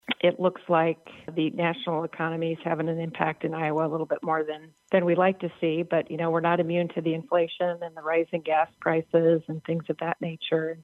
IOWA WORKFORCE DEVELOPMENT EXECUTIVE DIRECTOR, BETH TOWNSEND, SAYS CONDITIONS ON THE NATIONAL LEVEL FACTOR IN.